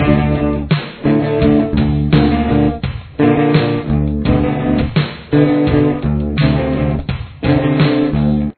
This riff utilizes drop D tuning.